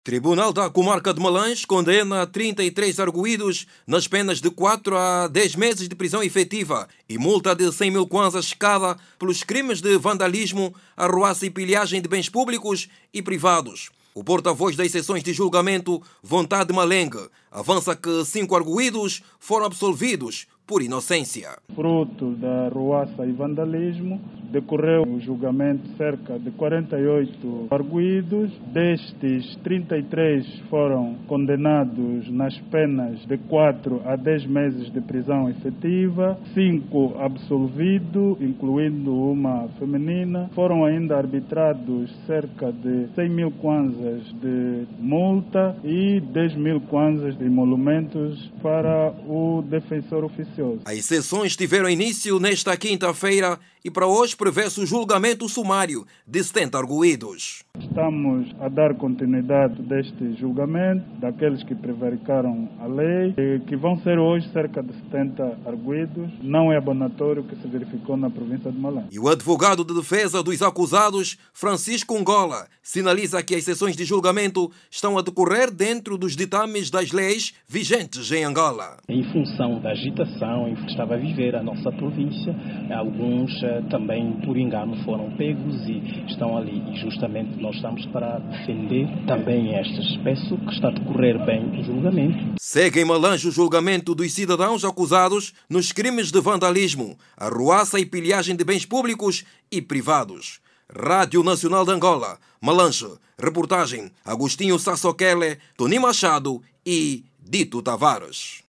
NOTÍCIAS